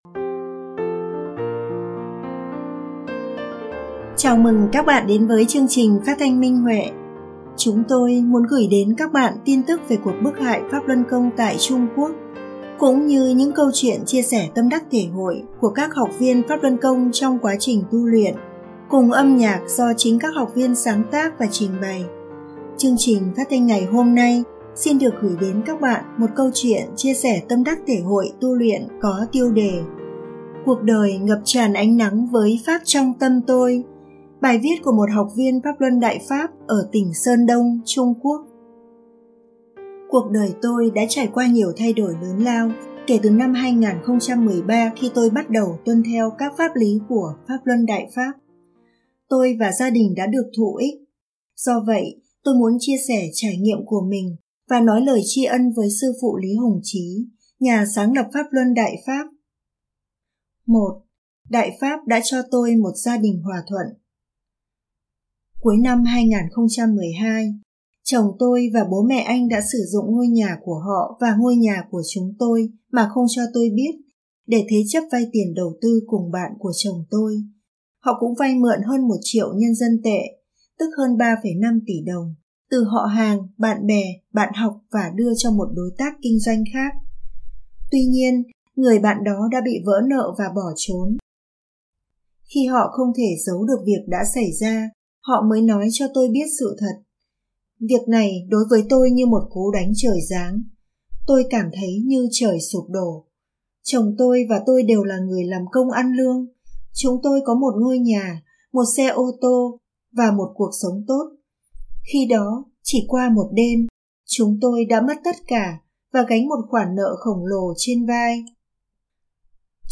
Chúng tôi muốn gửi đến các bạn tin tức về cuộc bức hại Pháp Luân Côngtại Trung Quốc cũng như những câu chuyện chia sẻ tâm đắc thể hội của các học viên trong quá trình tu luyện, cùng âm nhạc do chính các học viên sáng tác và trình bày.